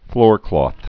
(flôrklôth, -klŏth)